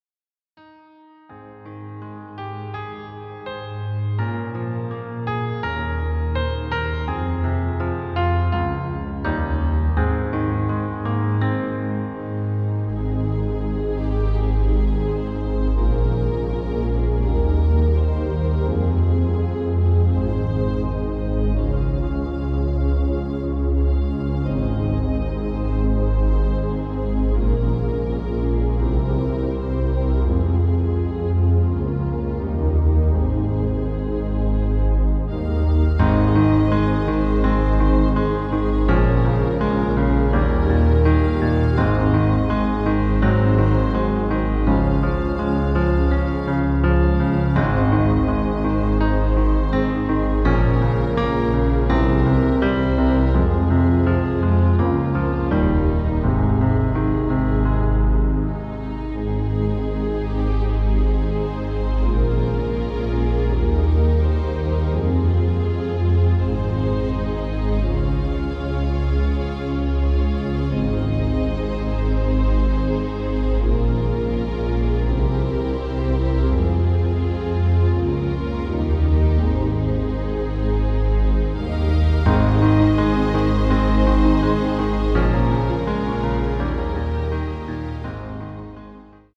• Tonart: Ab Dur, Bb Dur (Originaltonart )
• Art: Klavierversion mit Streichern
• Das Instrumental beinhaltet NICHT die Leadstimme
• Kurzes Vorspiel
• 3x Strophe + Refrain
• Kurzer Break
• Tonerhöhung